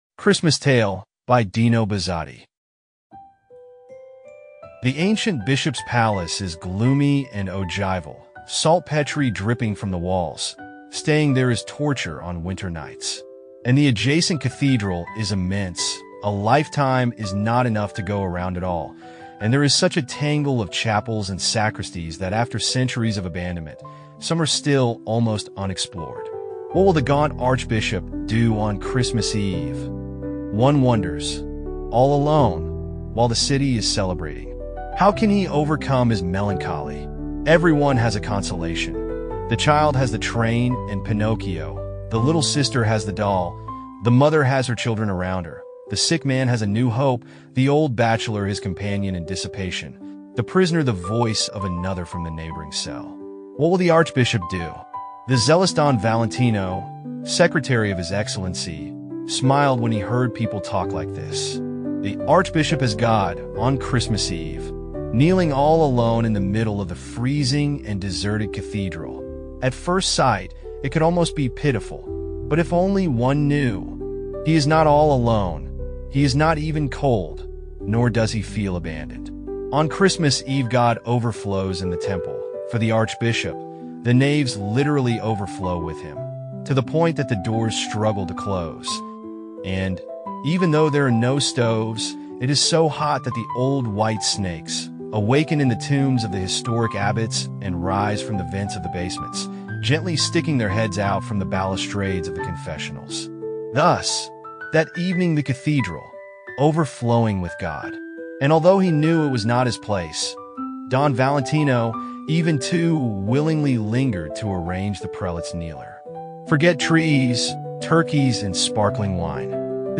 The interpreter is Andrew (AI).